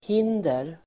Uttal: [h'in:der]